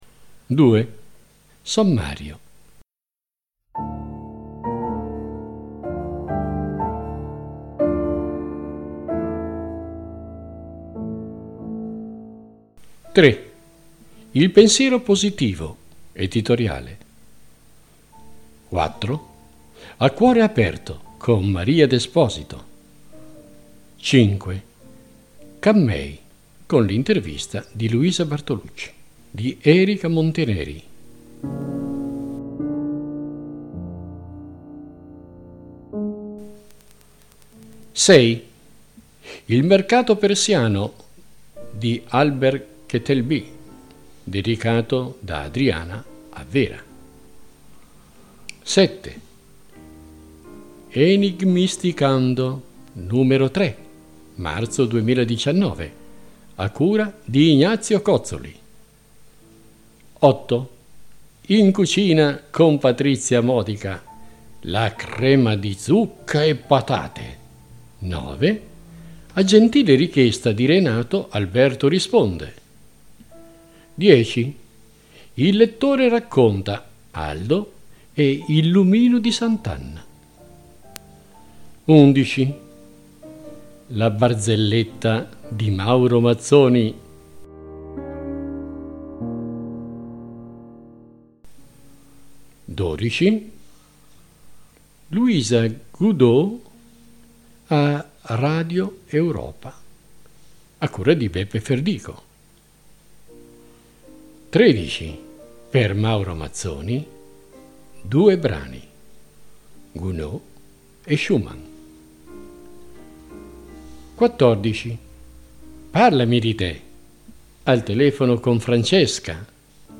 Il tutto è incorniciato da richieste musicali di vario genere che si incrociano, come proposte dai lettori, che arricchiscono l’audiorivista più tosta e superba del mondo.